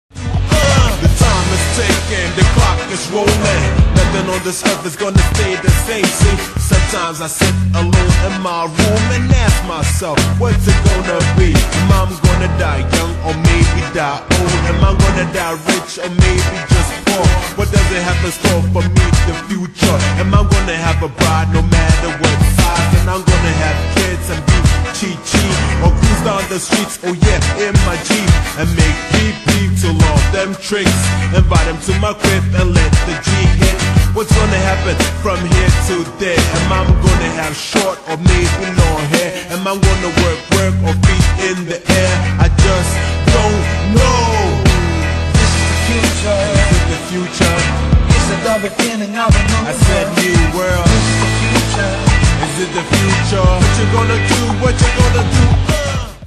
Rock-Mix